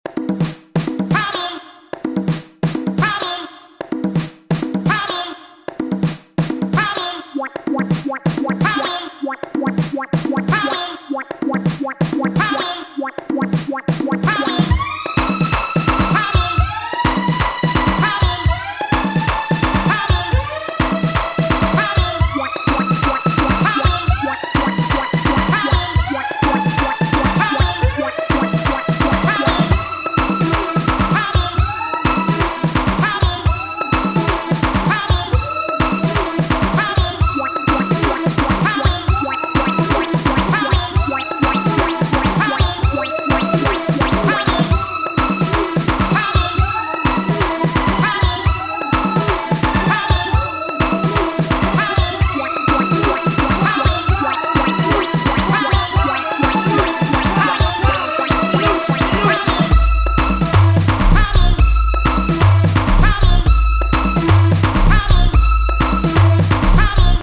jackin’ groove and eerie melody